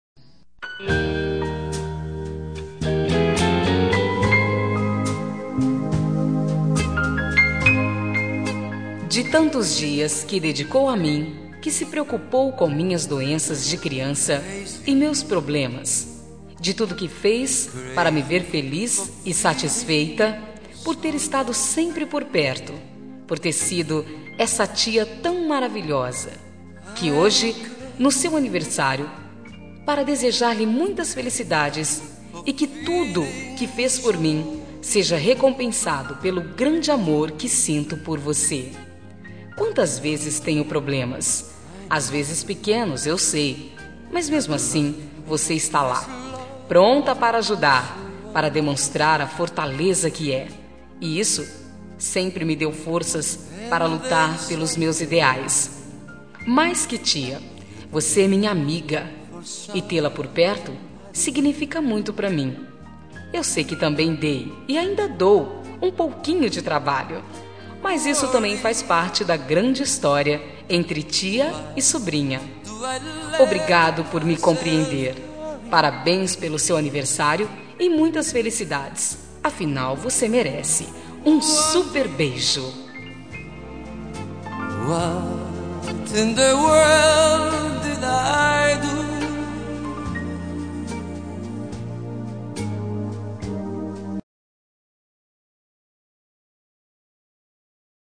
Telemensagem Aniversário de Tia – Voz Feminina – Cód: 1997